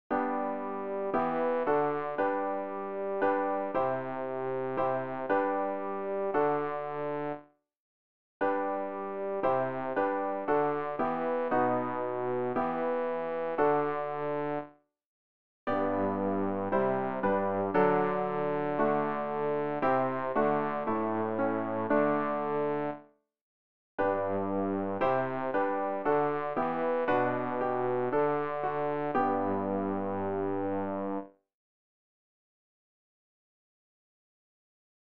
bass-rg-179-gott-vater-du-hast-deinen-namen.mp3